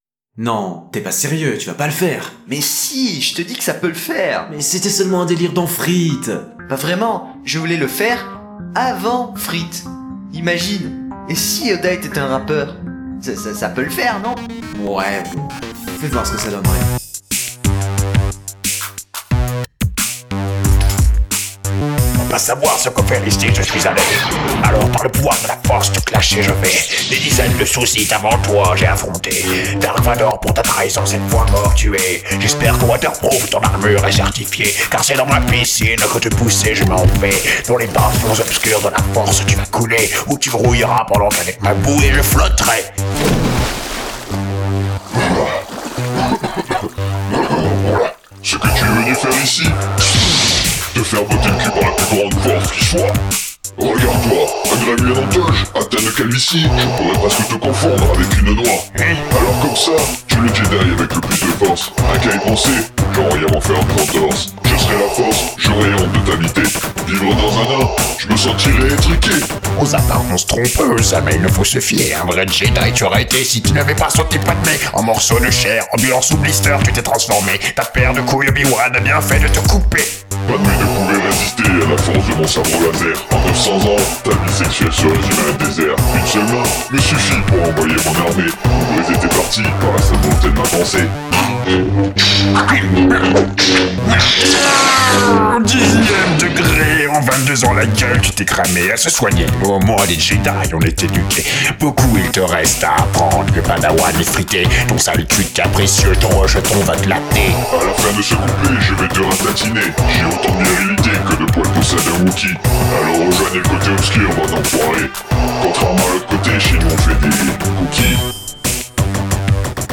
Synopsis : Imaginons que Yoda et Dark Vador s’affrontent dans une rap battle. les mots sont plus forts que les sabres lasers !
Mastering du rap battle